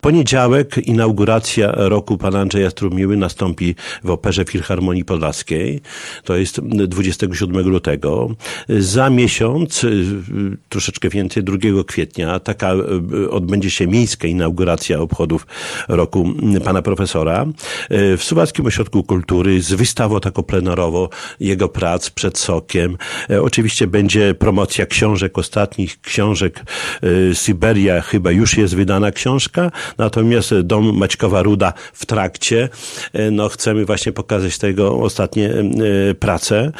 Na początku kwietnia w Suwalskim Ośrodku Kultury zaplanowano miejską inaugurację roku, później szereg wystaw, plener prac i w październiku benefis. Szczegóły przedstawił Czesław Renkiewicz, prezydent Suwałk.
Czesław-Renkiewicz-o-roku-strumiłły.mp3